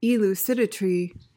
PRONUNCIATION:
(i-LOO/LYOO-si-duh-tuh-ree)